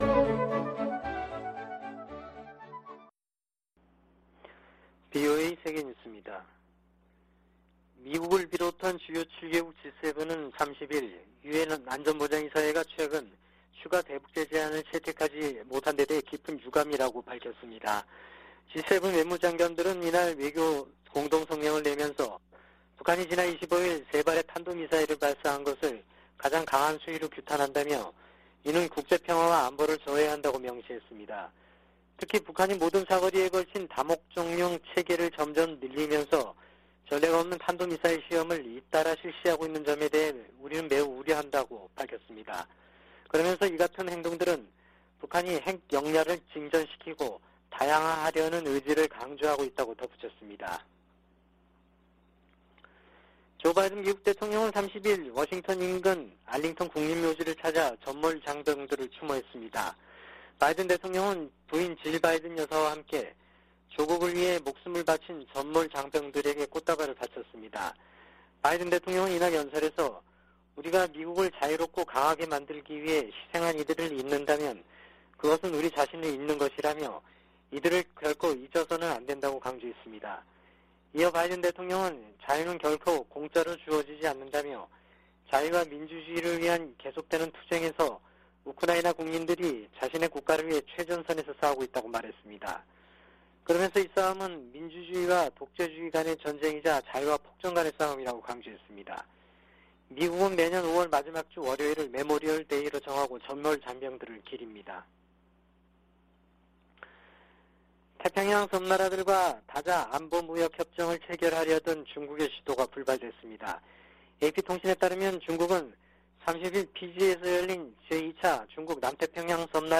VOA 한국어 아침 뉴스 프로그램 '워싱턴 뉴스 광장' 2022년 5월 31일 방송입니다. 미 재무부가 북한의 최근 탄도미사일 발사에 대응해 북한 국적자와 러시아 기관들을 추가 제재했습니다. 미한일 외교장관들은 유엔 안보리가 새 대북 결의안 채택에 실패한 데 유감을 나타내고 3국 협력은 물론 국제사회와의 조율을 강화하겠다고 밝혔습니다. 미 국방부는 북한이 계속 불안정을 야기한다면 군사적 관점에서 적절한 대응을 위한 방안을 찾을 것이라고 밝혔습니다.